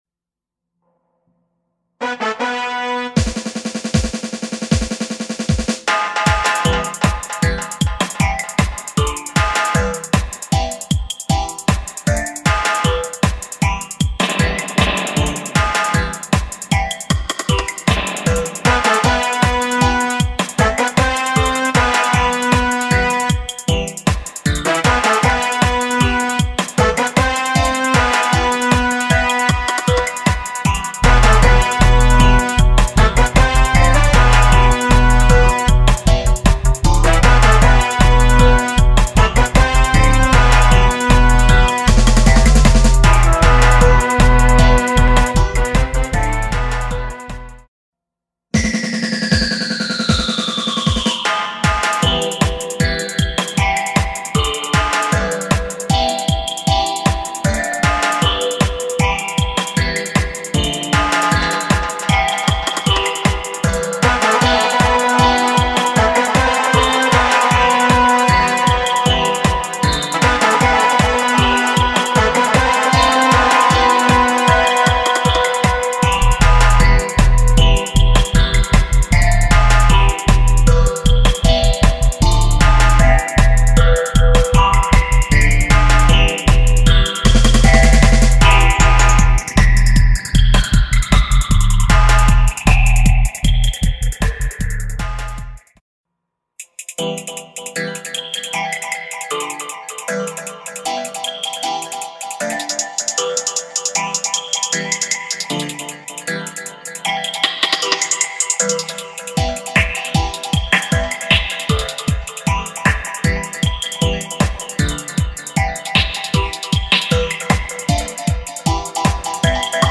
Three cut sampler of dubplate